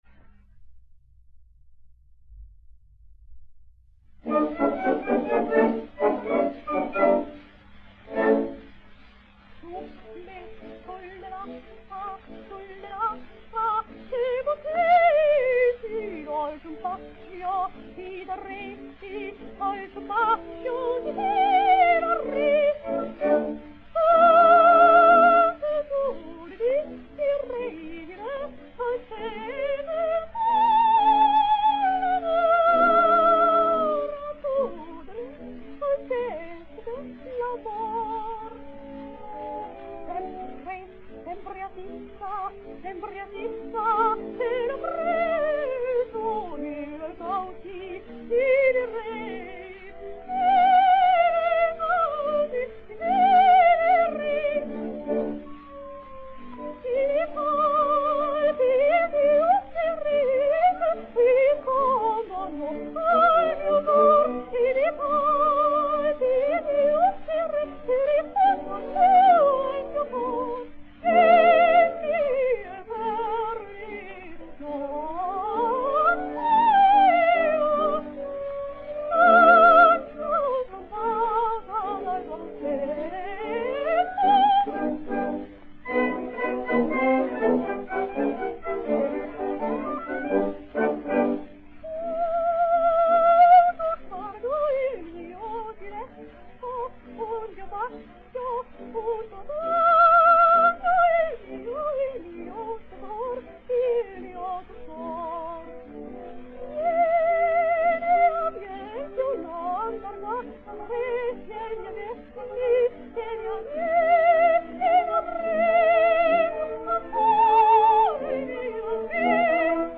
Sostituto della penultima ora, ma non da poco, perché tutte o quasi le cantanti coinvolte declinano (molte in chiave di soprano) il mito della grande primadonna con propensione a quella che oggi suole definirsi, con una punta di disprezzo, la baracconata.